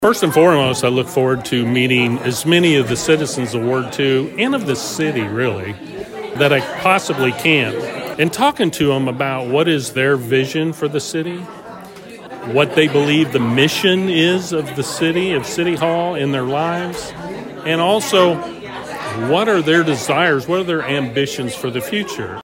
Dr. Kelso spoke with Thunderbolt news about what he looks forward to while serving on the board.